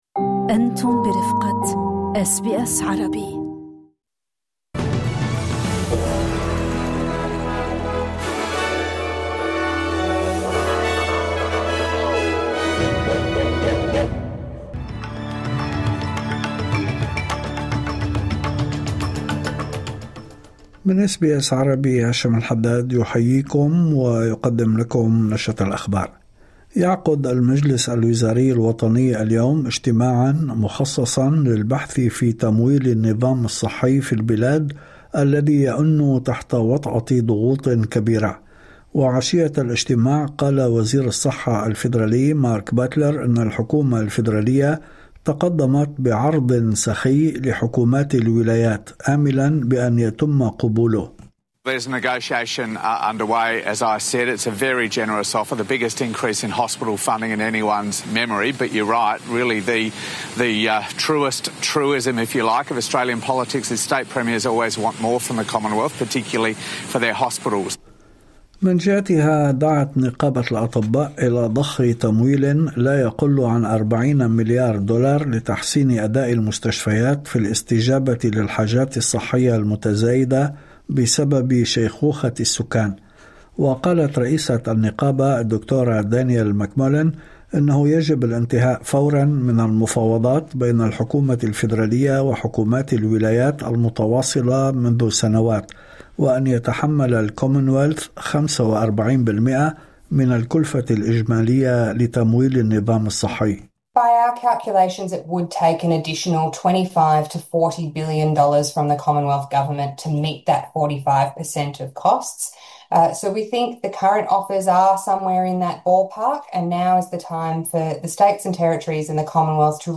نشرة أخبار الظهيرة 30/1/2026